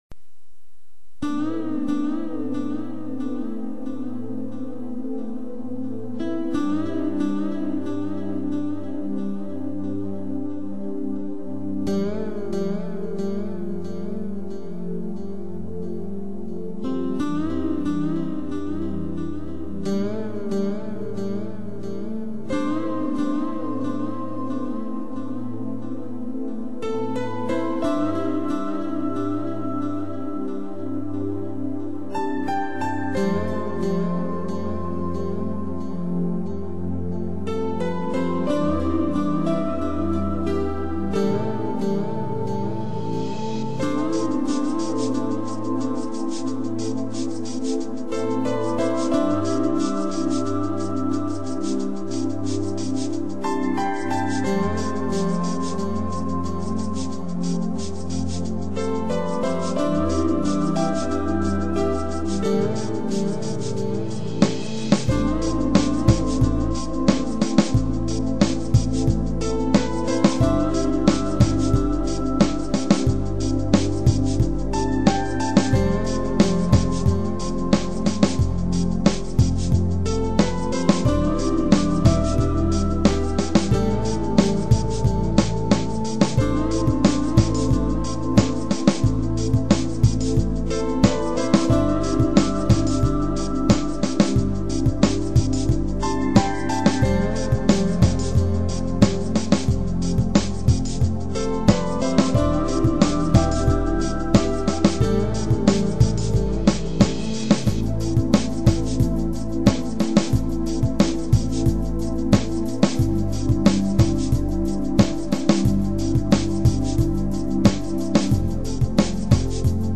【音乐类型】：NEW AGE
其音乐风格以电子音乐为主，并且广为融合民族音乐的